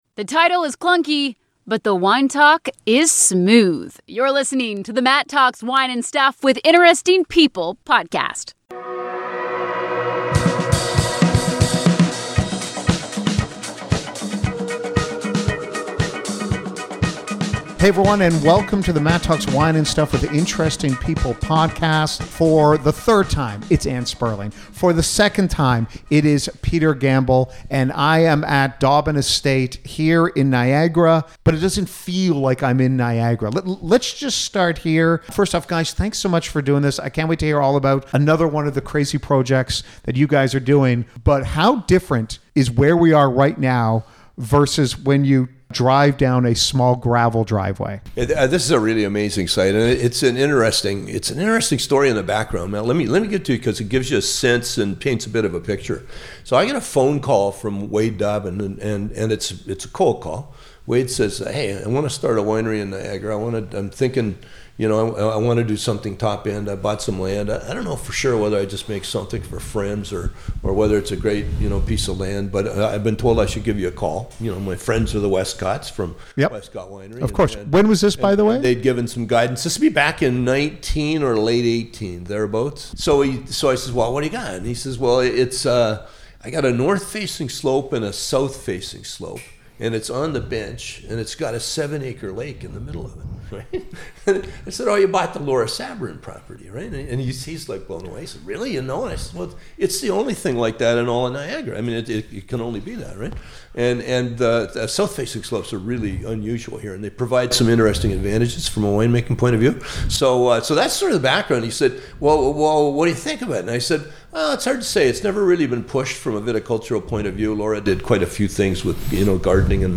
This was an epic interview